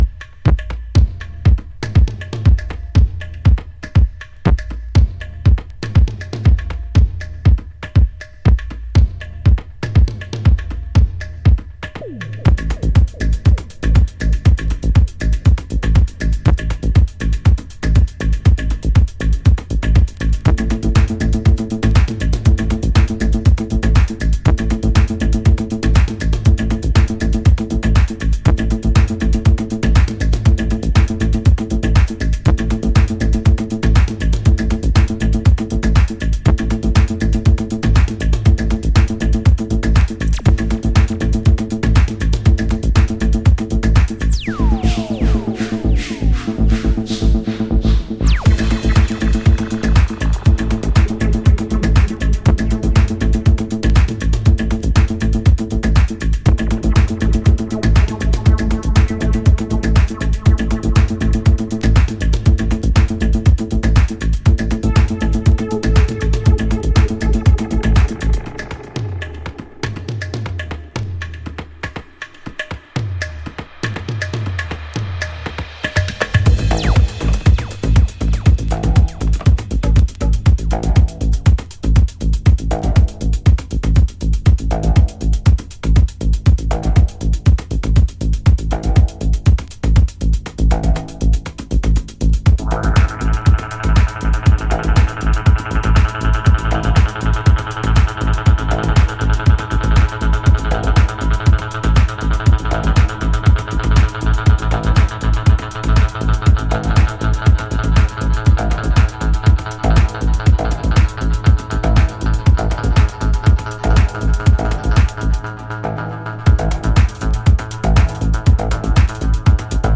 ヒプノティックで野太いエレクトリック・ハウス！
EXPERIMENTAL / EXTREME